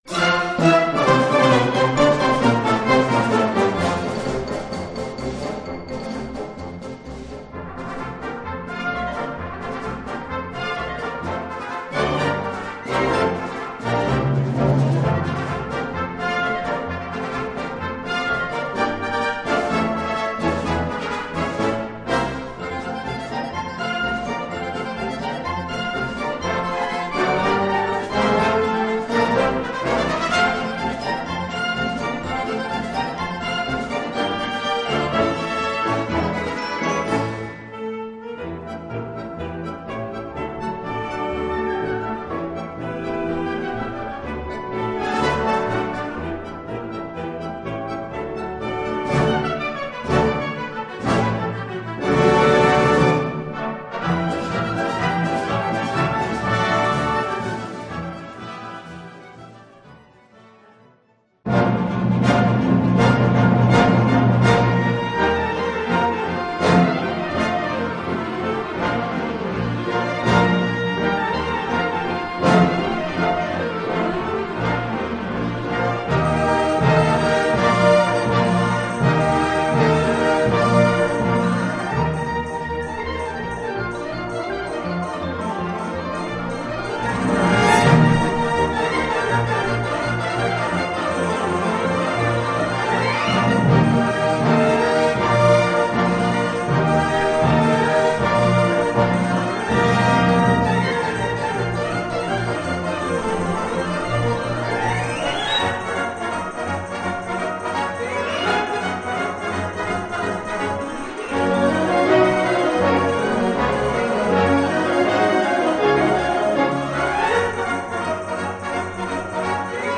Gattung: Suite
Besetzung: Blasorchester